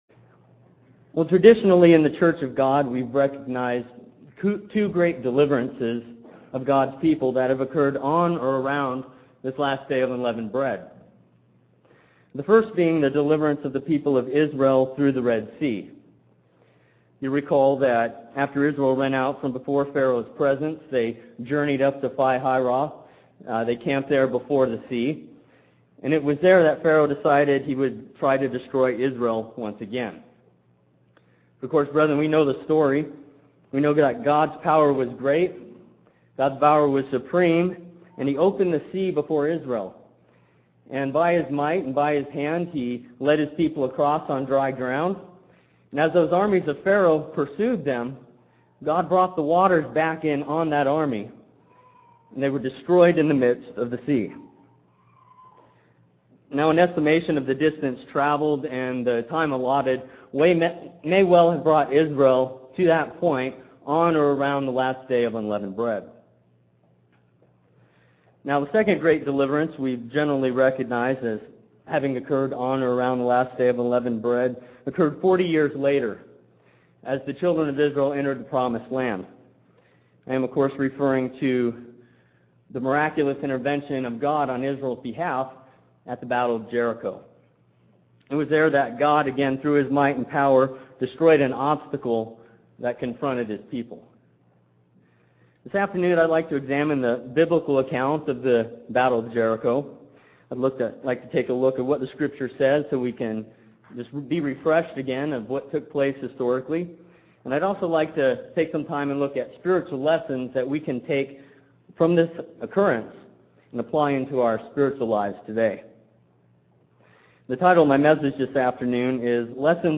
We can look at the Battle of Jericho for lessons on how to do this. This sermon includes three points that instruct us on how to more effectively get rid of the sins that remain in our lives. This message was given on the Last Day of Unleavened Bread.